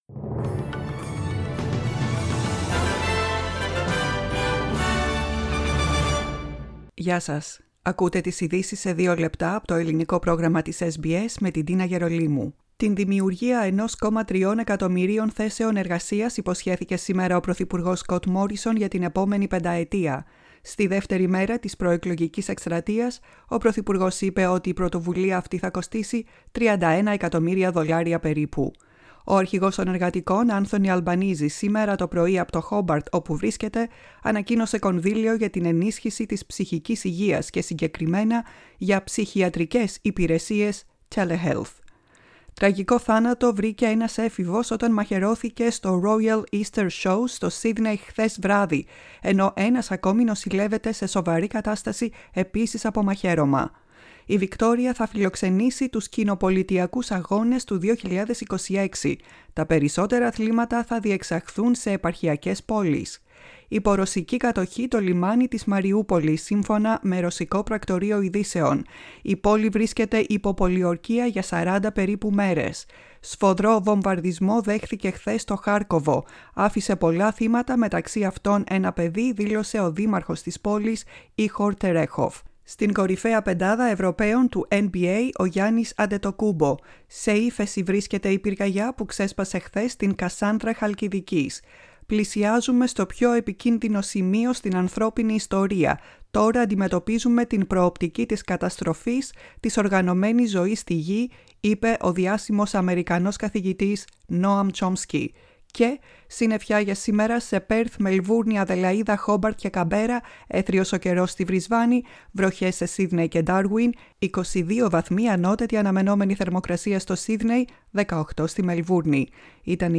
News Flash in Greek Source: SBS Radio